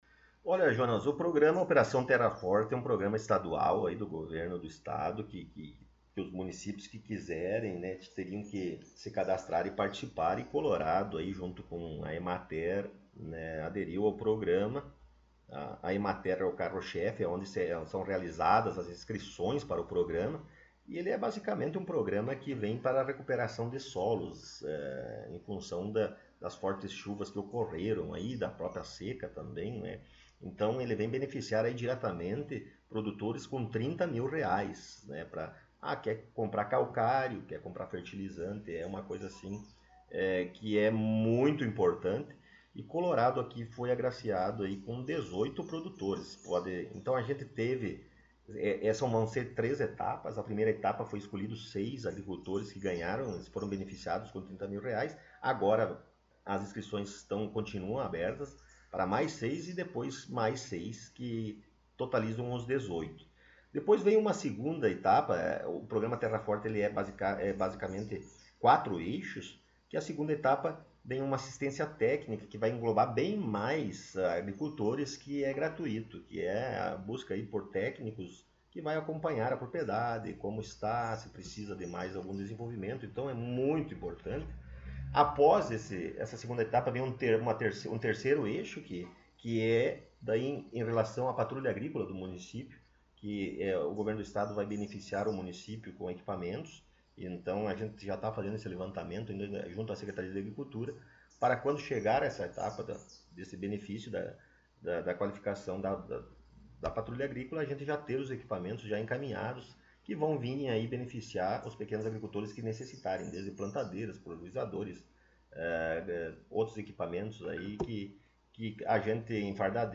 Após algumas semanas de espera, nossa equipe teve a oportunidade de entrevistar o prefeito Rodrigo Sartori em seu gabinete na Prefeitura Municipal.